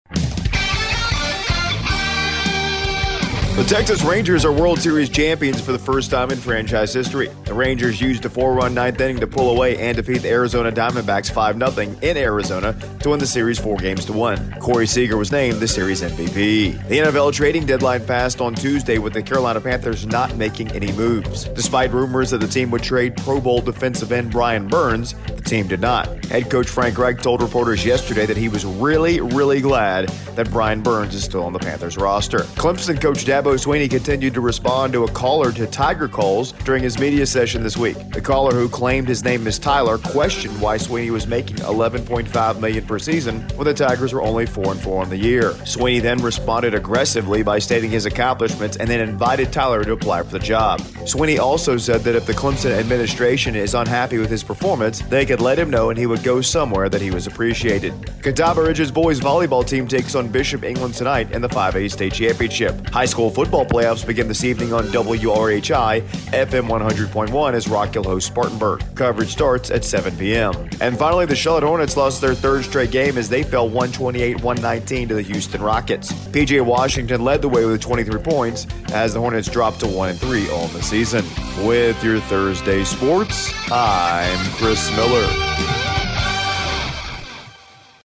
AUDIO: Thursday Morning Sports Report